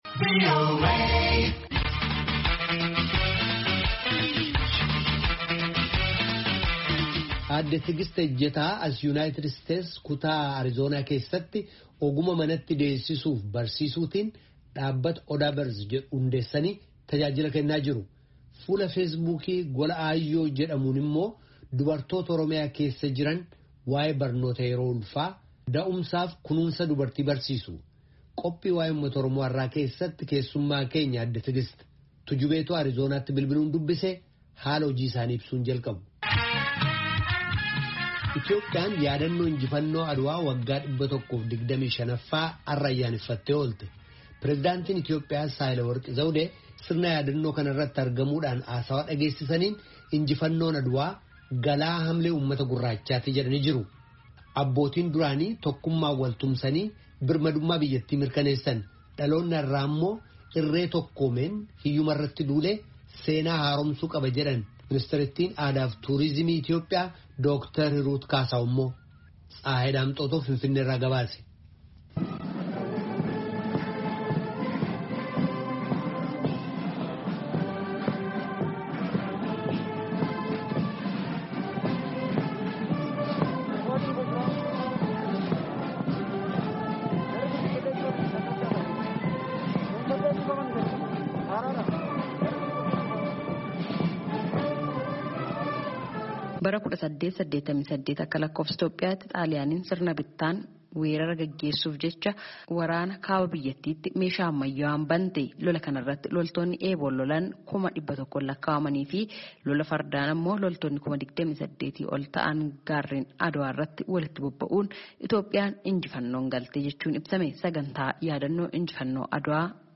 Finfinnee irraa gabaase